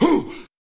Wooo Chant.wav